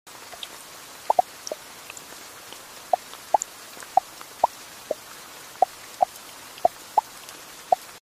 Rain on her skin. No sound effects free download
No words.
Rain, skin, and silence.